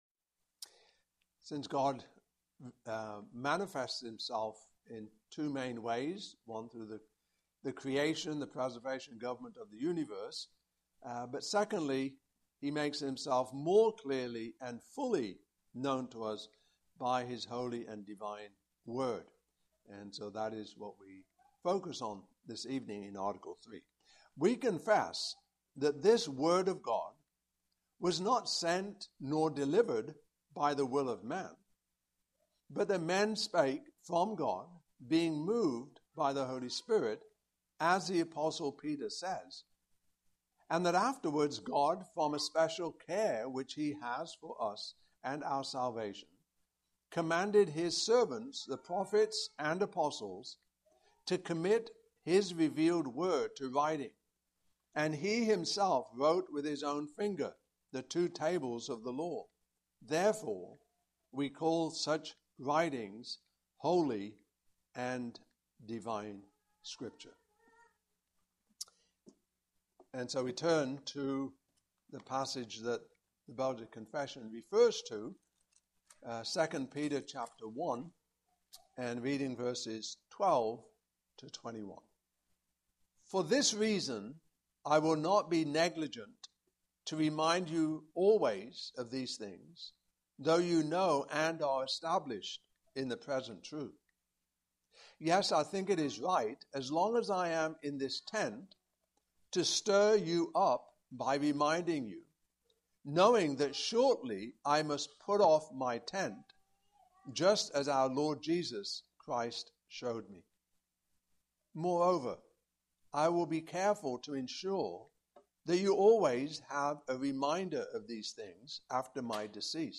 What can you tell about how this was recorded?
Passage: 2 Peter 1:12-21 Service Type: Evening Service